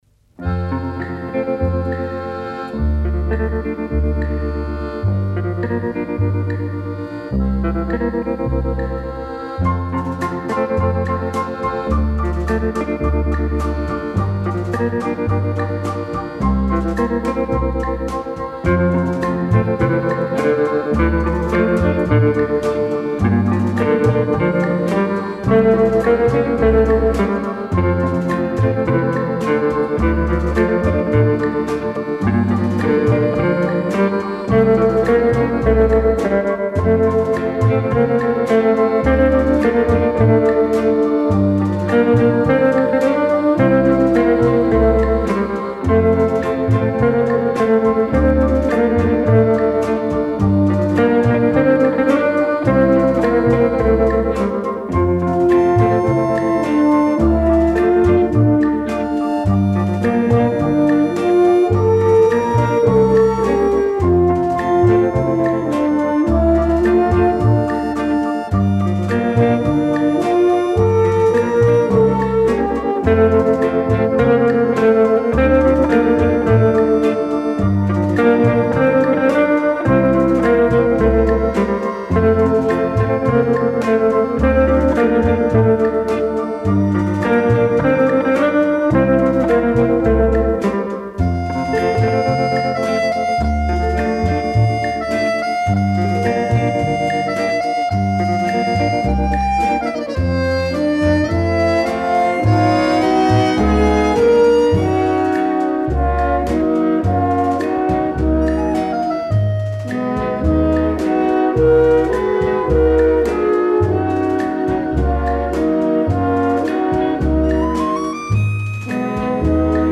Танго